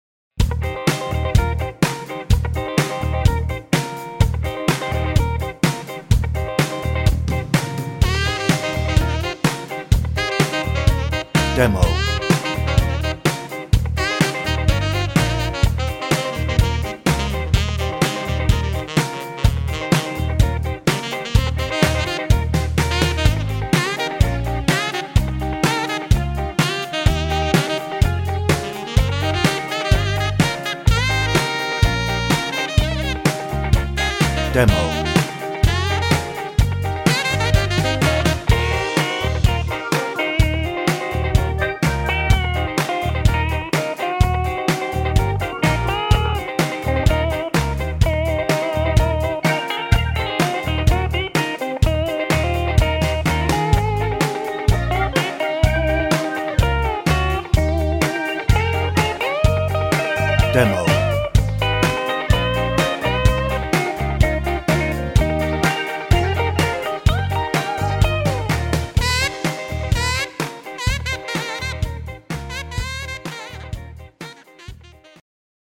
Hoedown - No ref. vocal
Instrumental